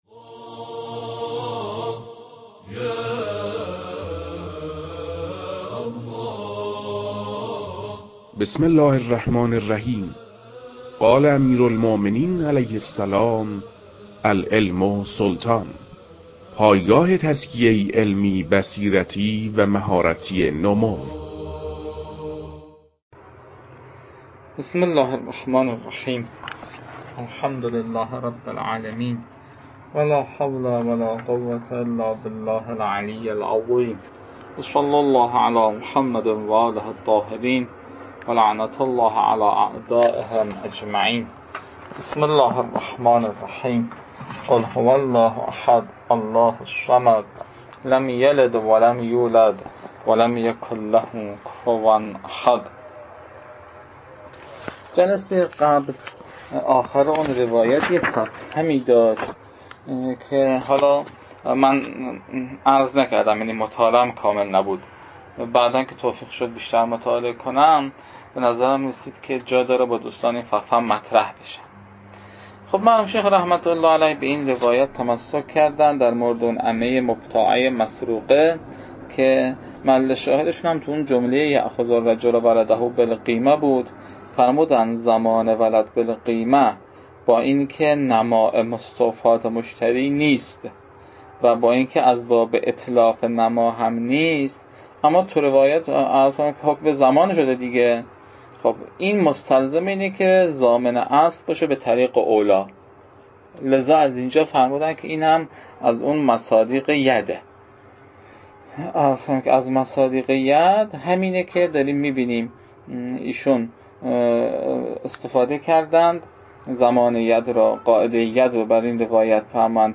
در این بخش، فایل های مربوط به تدریس مباحث تنبیهات معاطات از كتاب المكاسب متعلق به شیخ اعظم انصاری رحمه الله